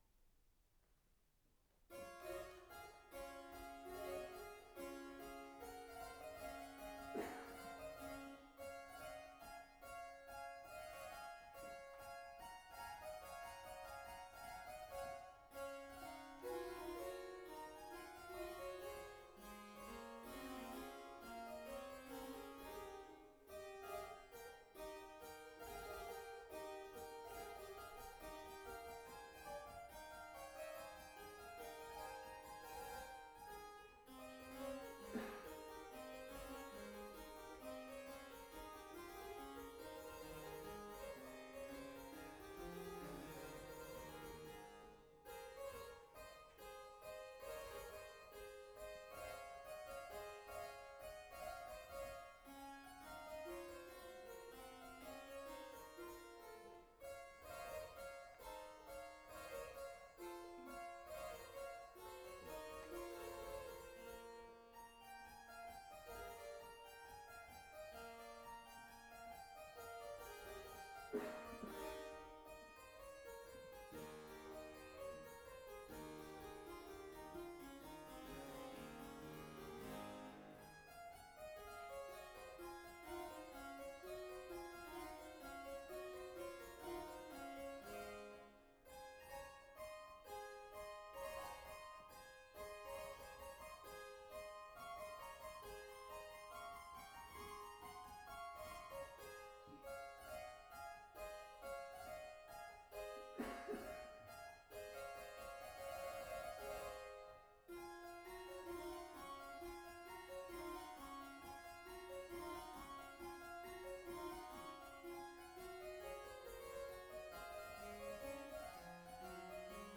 バロック発表会2025　チェンバロを演奏しました